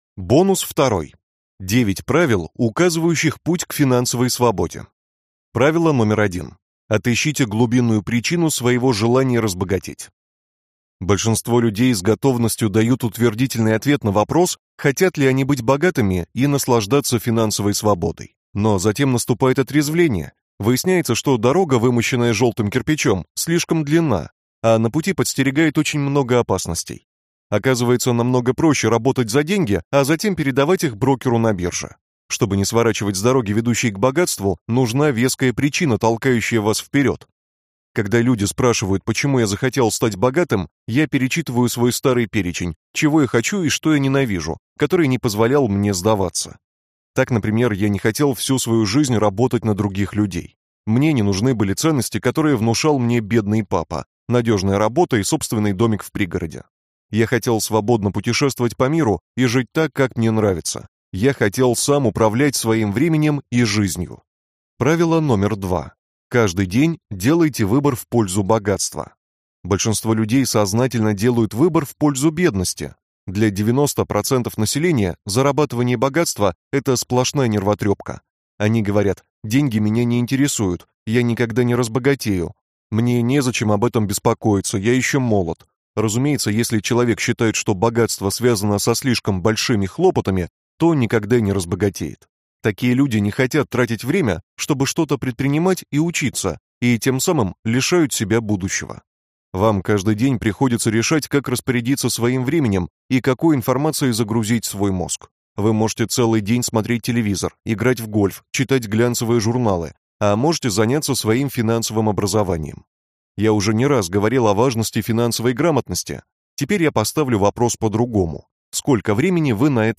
Аудиокнига Цели и решения - купить, скачать и слушать онлайн | КнигоПоиск